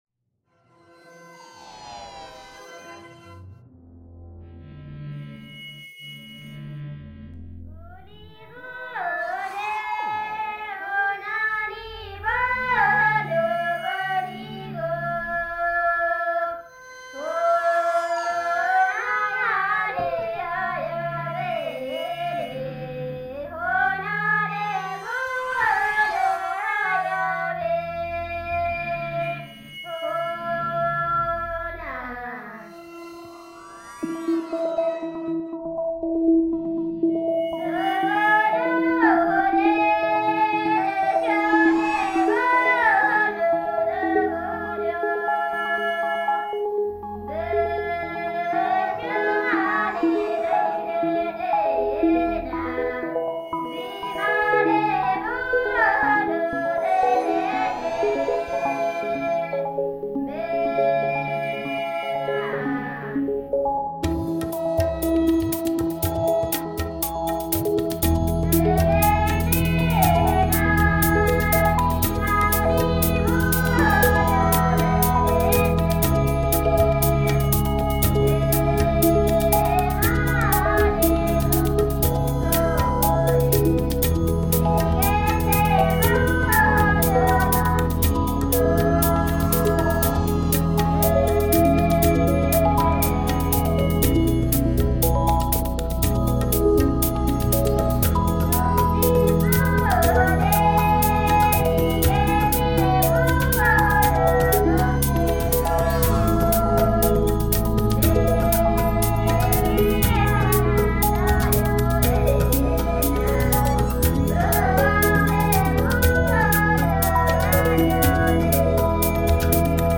The chanting is sacred - but it is also joyous.
Women singing reimagined